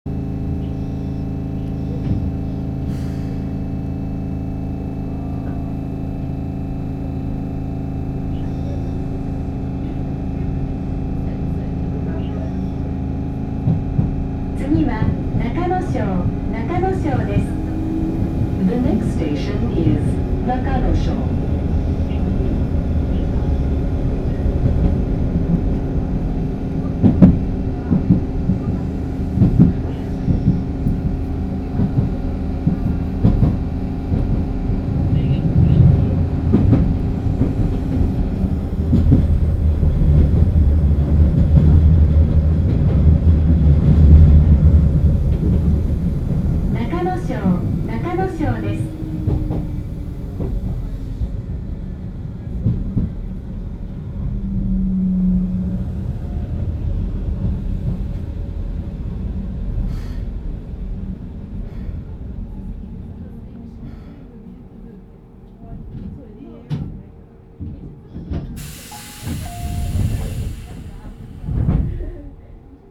走行機器は抵抗制御で、定格53kWのTDK-8565A複巻整流子モーターを制御します。
走行音
録音区間：瓦ヶ浜～中ノ庄(お持ち帰り)